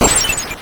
IcicleCrash.wav